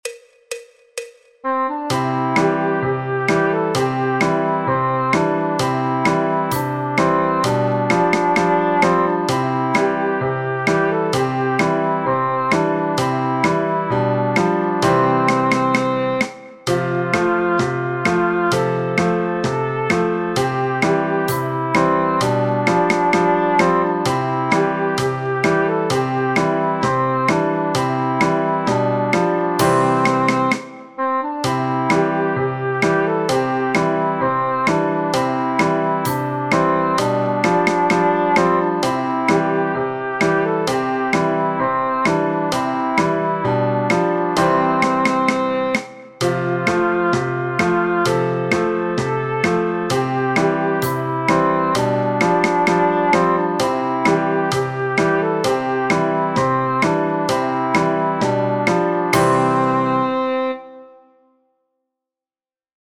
El MIDI tiene la base instrumental de acompañamiento.
Folk, Popular/Tradicional